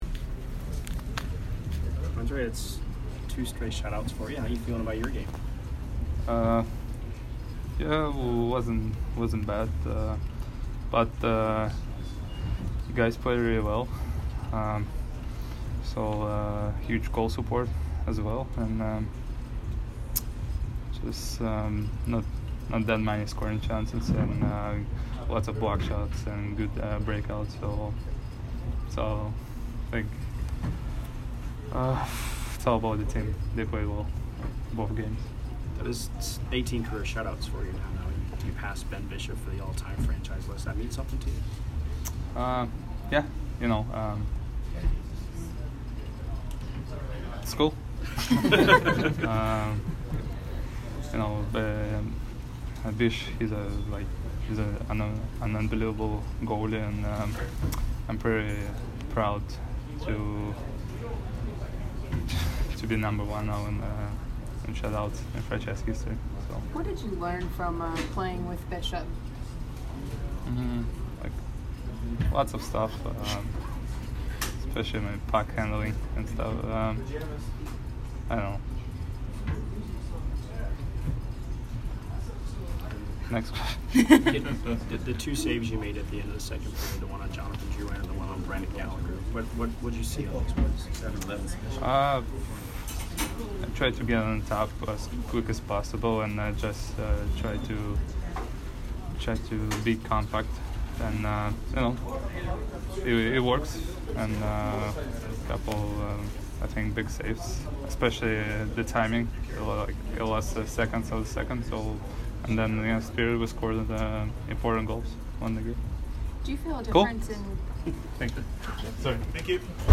Andrei Vasilevskiy post-game 2/16